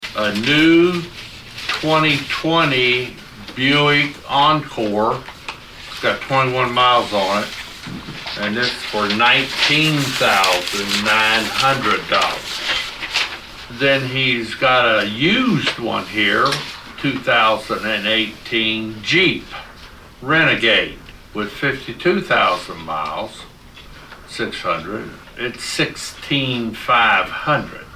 Guthrey then read off the two bids from McCarthy.